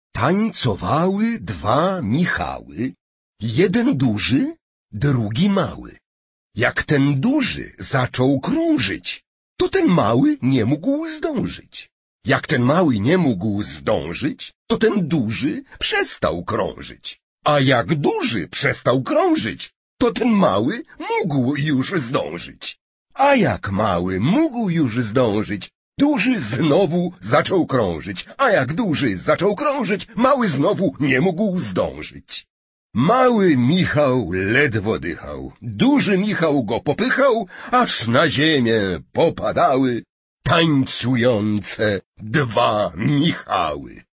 Смотрим на текст, слушаем голос польского актёра Петра Фрончевского — и всё-всё понимаем!..
Юлиан Тувим, стихотворение «Dwa Michały»: